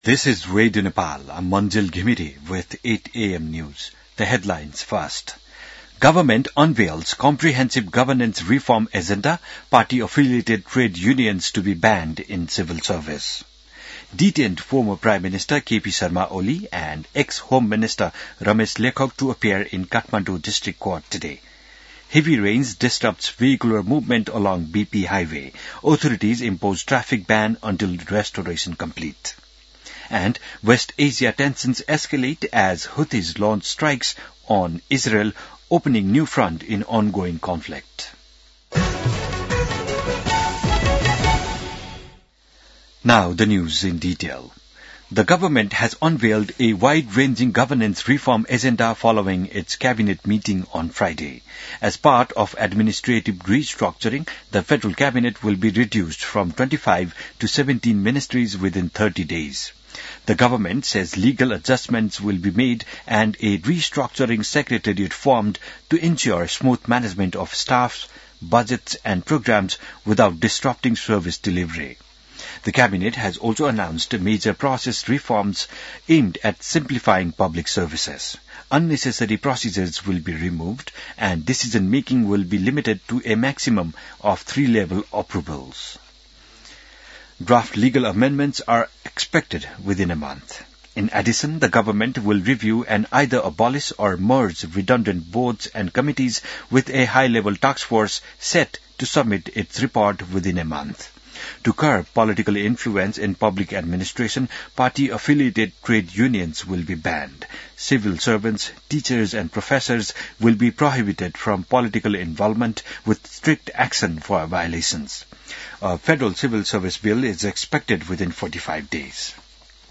बिहान ८ बजेको अङ्ग्रेजी समाचार : १५ चैत , २०८२